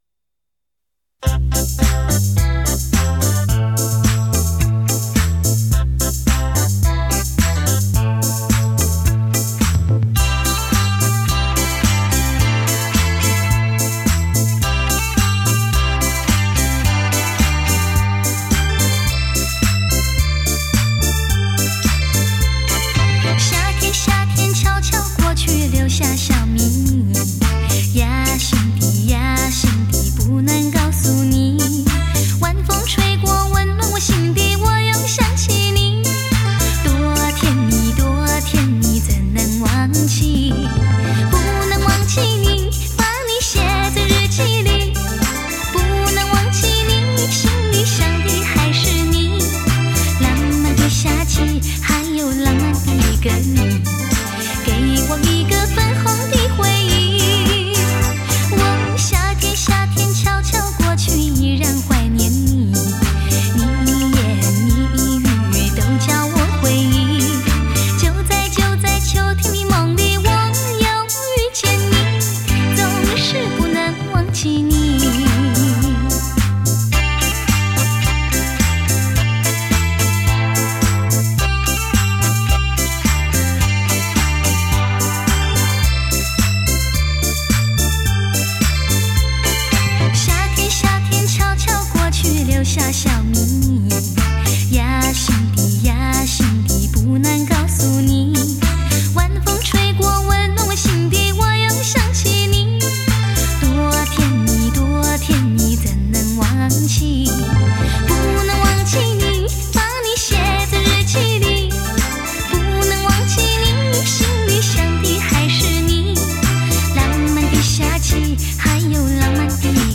甜歌天后